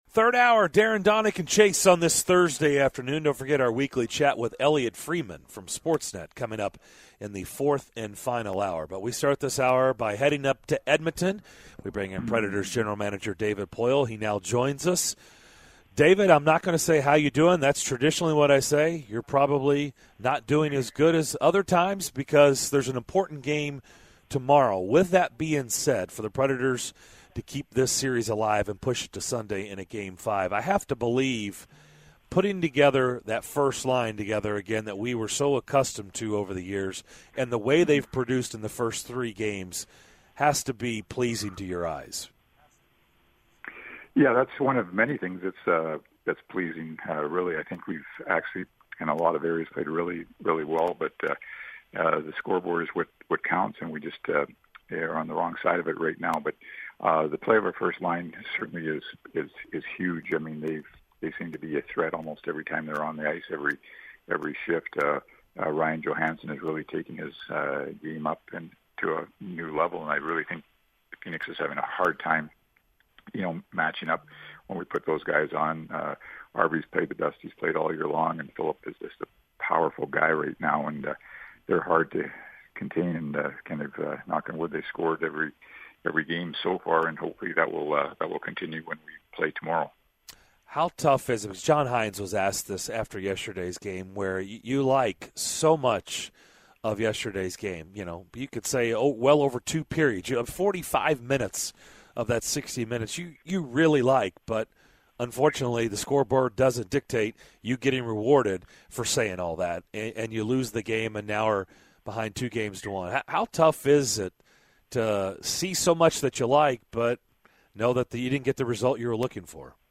Nashville Predators GM David Poile joined DDC to discuss the Preds' disappointing loss in Game 3 and what he wants to see from the team moving forward!